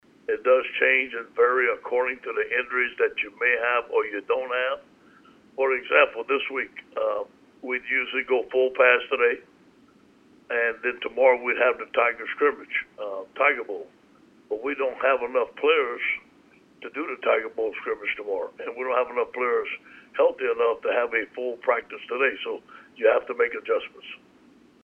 Coach Orgeron plans to have light practices for the players, who need to let their bodies rest before the last month of the season. During the SEC media call on Wednesday, he stated that LSU follows a formula regarding practices during the bye-week.